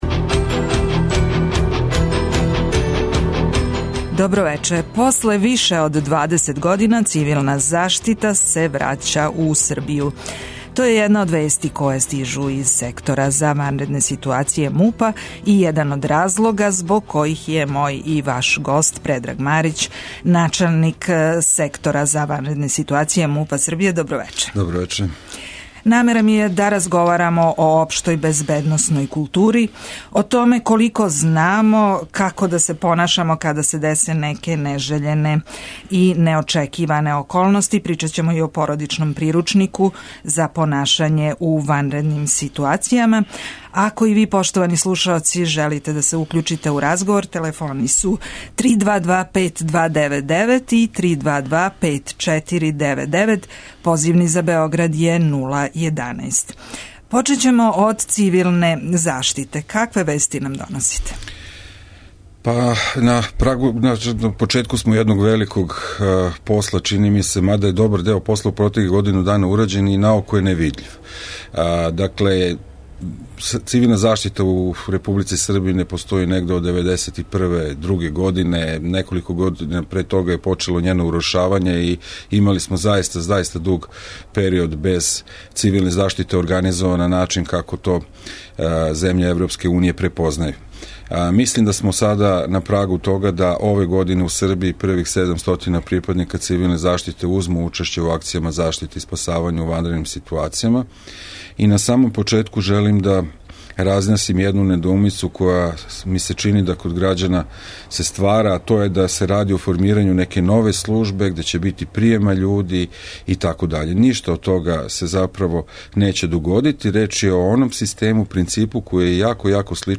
Гост емисије је Предраг Марић, начелник Сектора МУП-а за ванредне ситуације, који је пре неколико дана најавио могућност да 2013. буде година поновног успостављања цивилне заштите у Србији, после више од две деценије.
преузми : 25.94 MB У средишту пажње Autor: Редакција магазинског програма Свакога радног дана емисија "У средишту пажње" доноси интервју са нашим најбољим аналитичарима и коментаторима, политичарима и експертима, друштвеним иноваторима и другим познатим личностима, или личностима које ће убрзо постати познате.